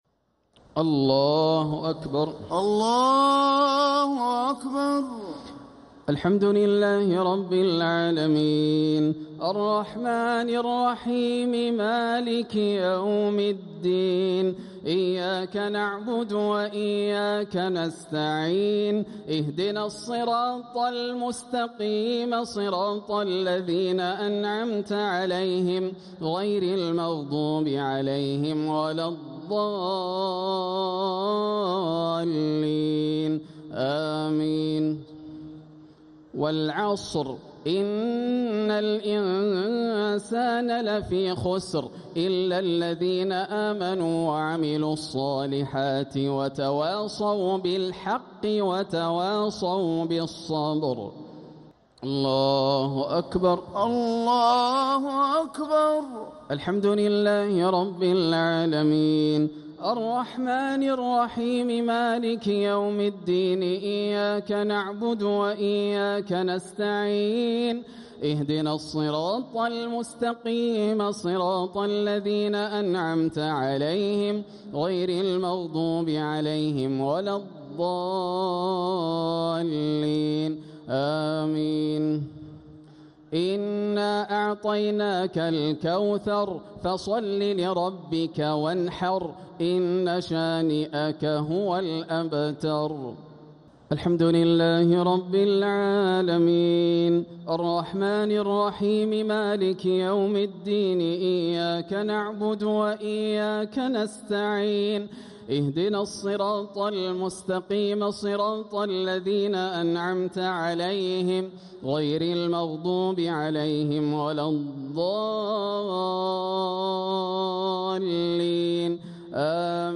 صلاة الشفع و الوتر ليلة 6 رمضان 1447هـ | Witr 6th night Ramadan 1447H > تراويح الحرم المكي عام 1447 🕋 > التراويح - تلاوات الحرمين